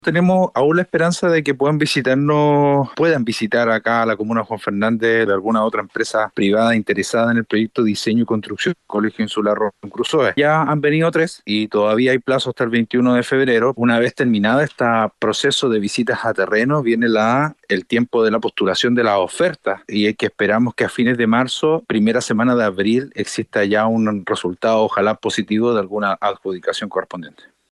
En relación a este proceso de licitación, el alcalde de Juan Fernández, Pablo Manríquez, declaró que espera que, a fines de marzo e inicios de abril, exista un resultado positivo de la adjudicación del proyecto.